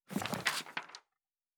Fantasy Interface Sounds